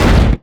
IMPACT_Generic_10_mono.wav